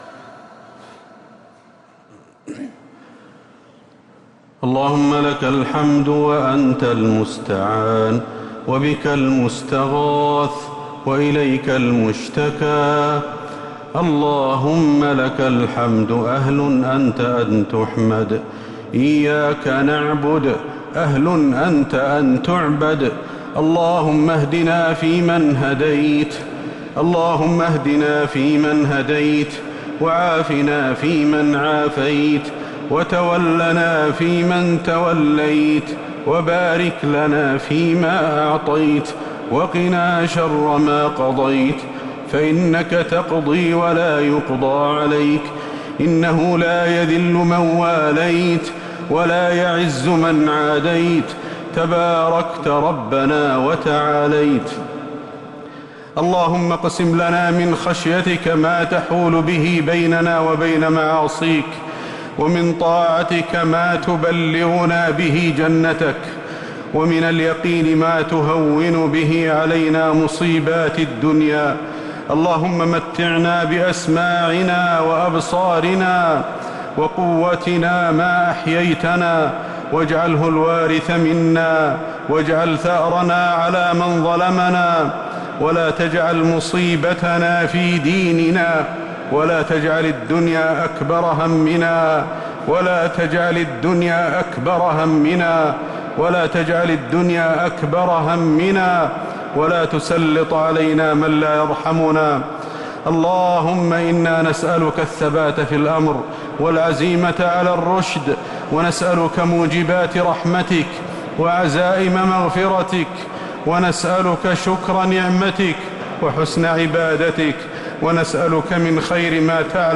دعاء القنوت ليلة 20 رمضان 1447هـ | Dua20th night Ramadan 1447H > تراويح الحرم النبوي عام 1447 🕌 > التراويح - تلاوات الحرمين